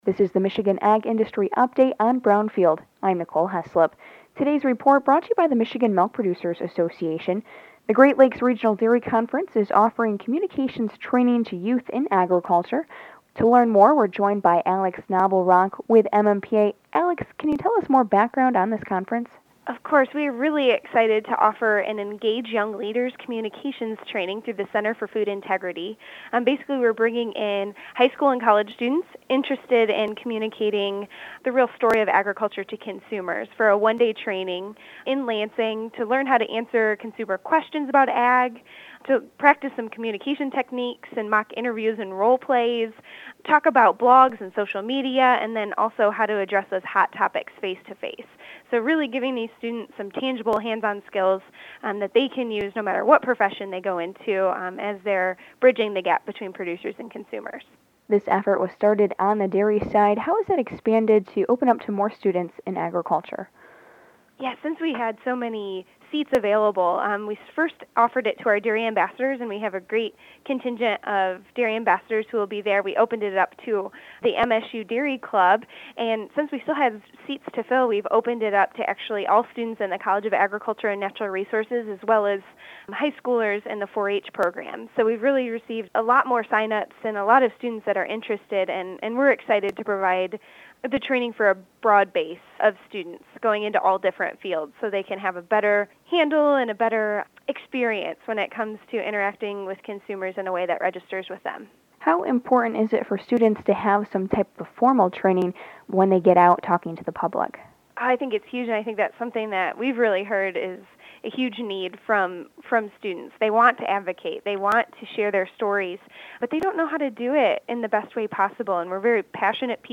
AUDIO: Interview